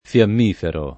[ f L amm & fero ]